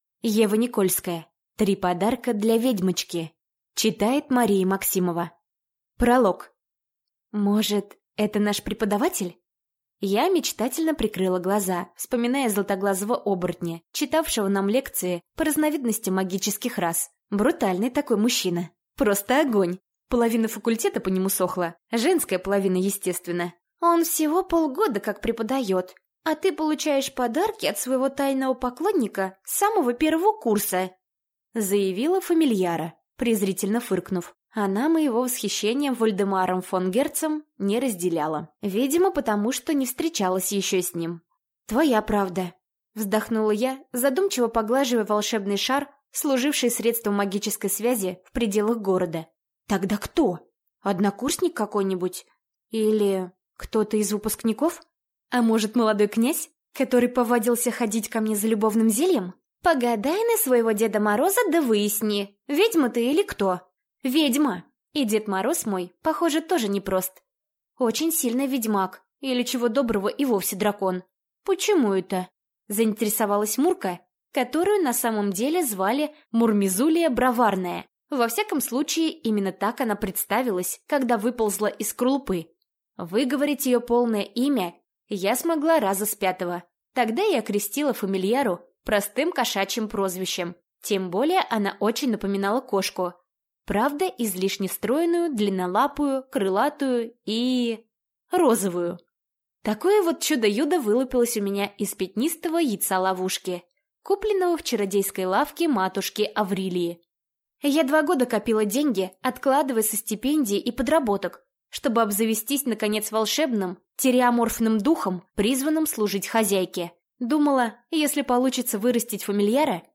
Аудиокнига Три подарка для ведьмочки | Библиотека аудиокниг